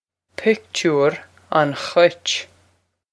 Pronunciation for how to say
pick-toor on khwitch
This is an approximate phonetic pronunciation of the phrase.